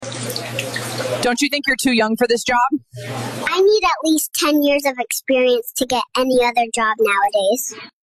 💀😂 AI skits are exposing the job market better than the news ever could.